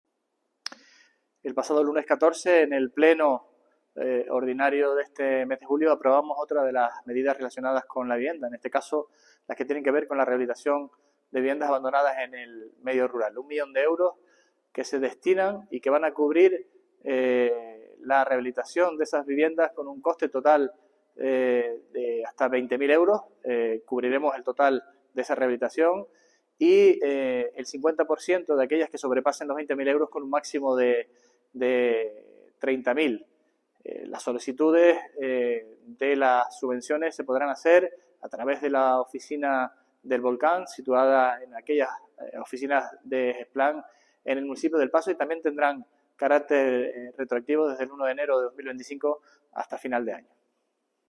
Declaraciones audio Sergio Rodríguez viviendas rurales.mp3